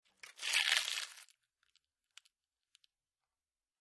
Slower Bite Into Chips, X7